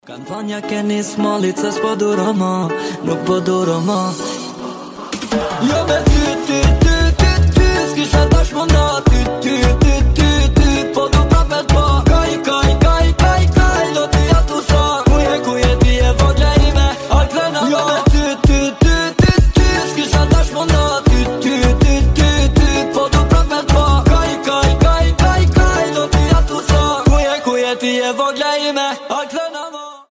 • Качество: 320, Stereo
восточные
качающие
арабские